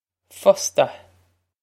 fosta fus-ta
Pronunciation for how to say
This is an approximate phonetic pronunciation of the phrase.